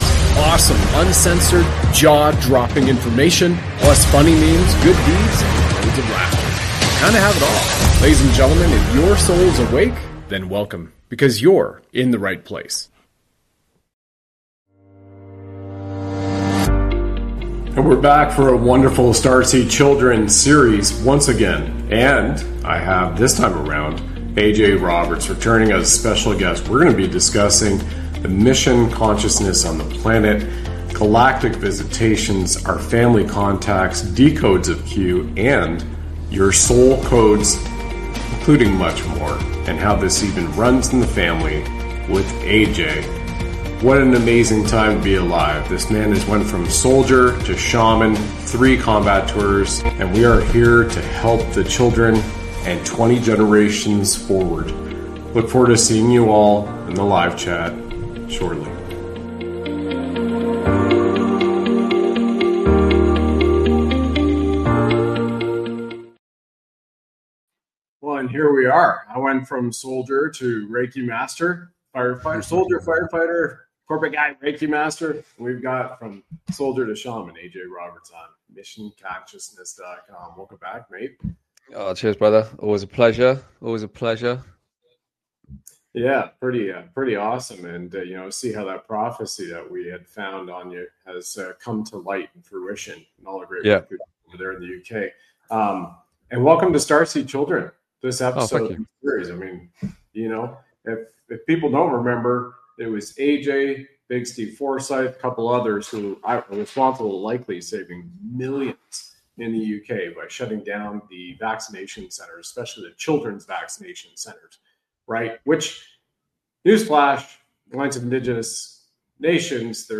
Two friends share their journeys from soldiers to spiritual healers, highlighting the power of personal change. They discuss self-awareness, shedding old traumas, and creating one's reality. Emphasizing trust in one's path, they find joy in peace and gratitude.